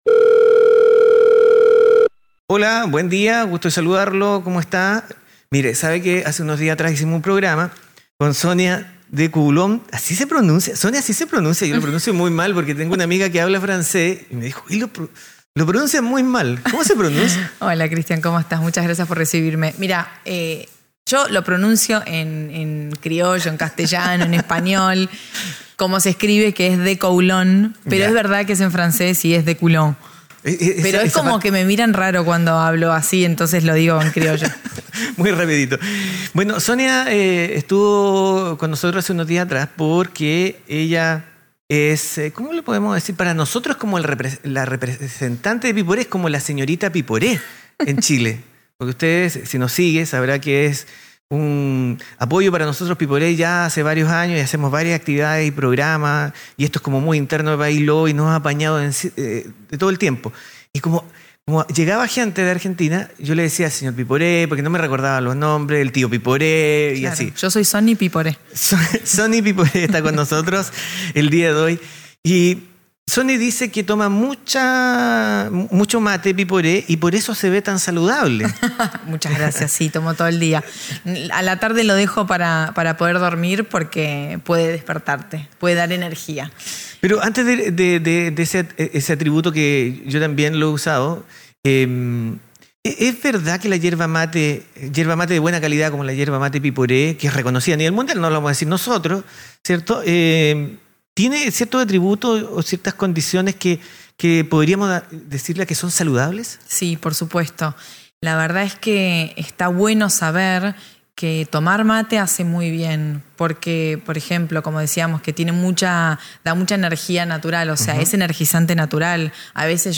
En sus visita a Chile, tuvimos una distendida conversacíón